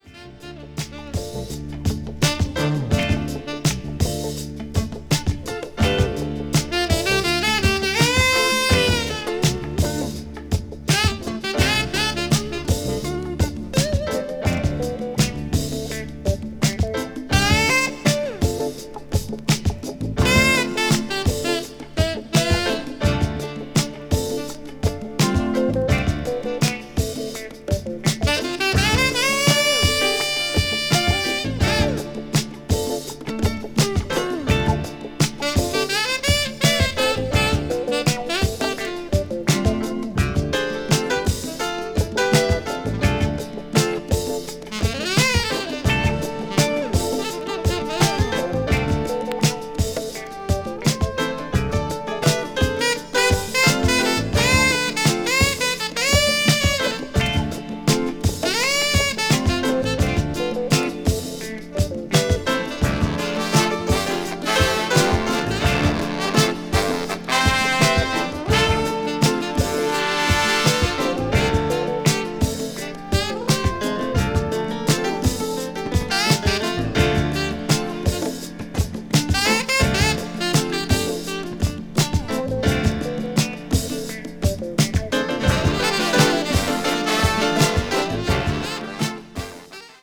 jazz funk   jazz groove   r&b   soul   soul jazz